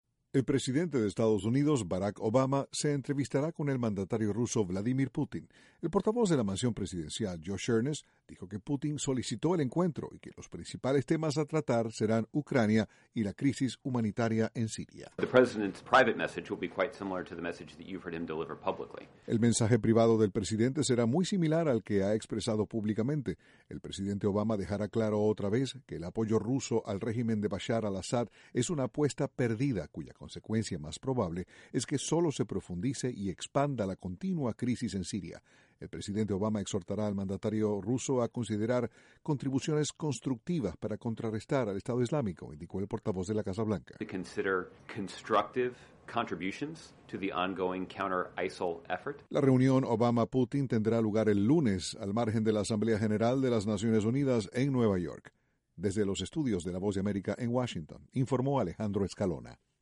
Los presidentes de Estados Unidos y Rusia, Barack Obama y Vladimir Putin, se reunirán el lunes en Nueva York. Desde la Voz de América, Washington, informa